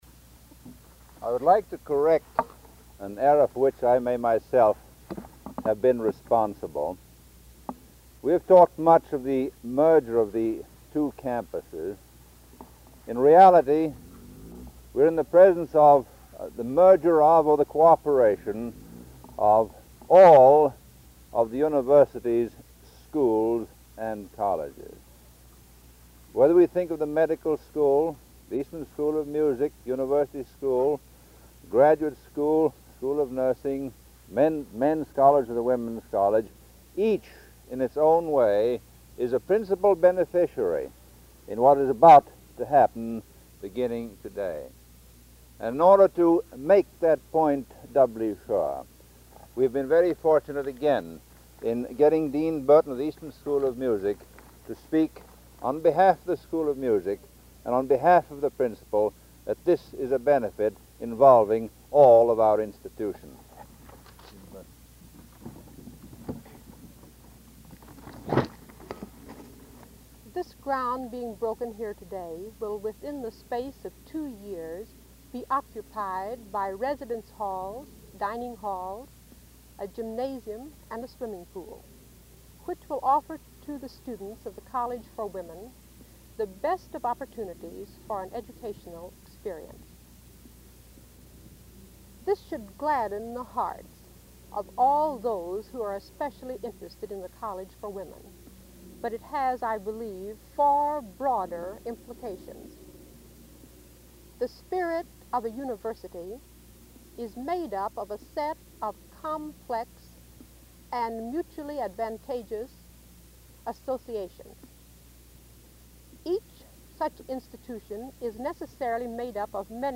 1952 Groundbreaking for Susan B. Anthony Hall and Spurrier Gymnasium
Remarks